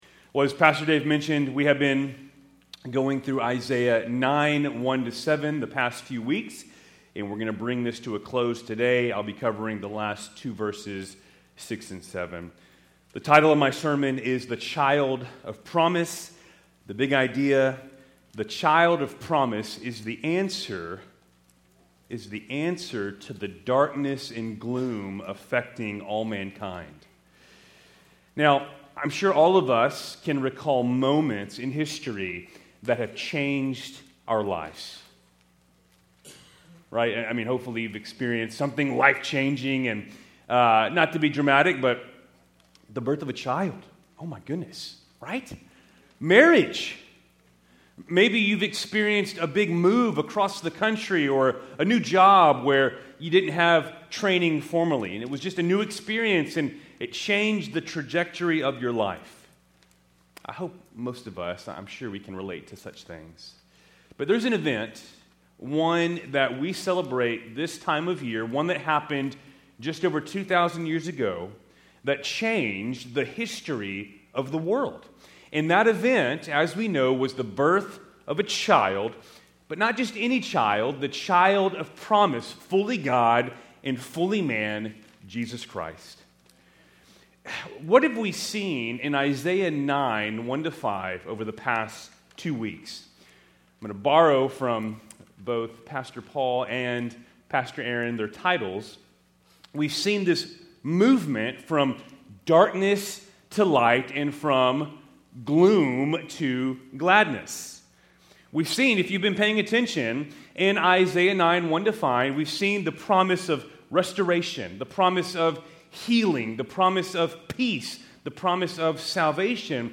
Keltys Worship Service, December 22, 2024